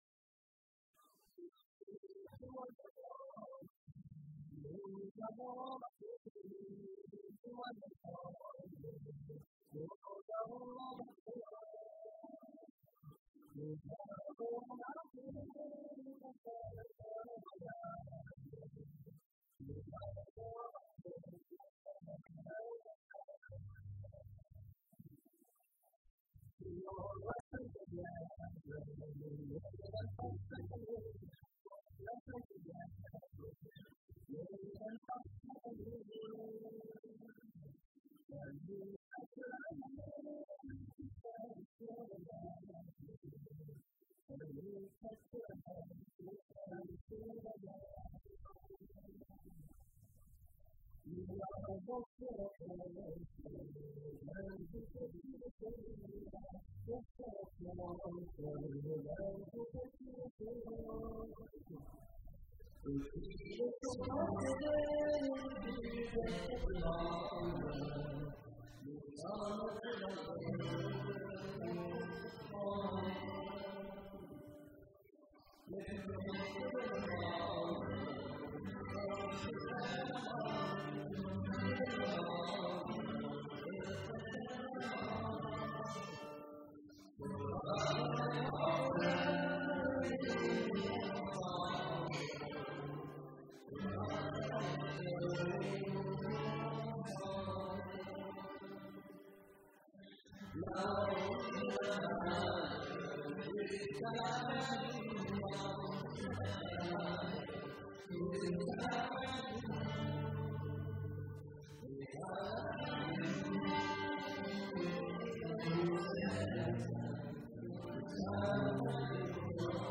chanson recueillie
Pièce musicale éditée